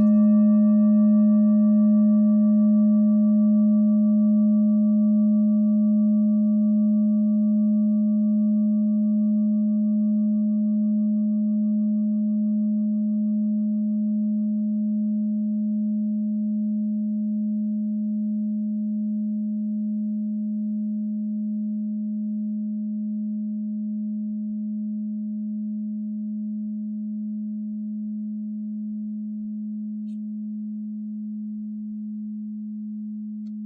Klangschale Bengalen Nr.25
Sie ist neu und wurde gezielt nach altem 7-Metalle-Rezept in Handarbeit gezogen und gehämmert.
(Ermittelt mit dem Filzklöppel)
Der Neptunton liegt bei 211,44 Hz, das ist nahe beim "Gis".
klangschale-ladakh-25.wav